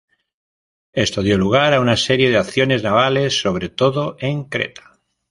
Pronounced as (IPA) /ˈkɾeta/